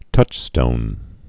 (tŭchstōn)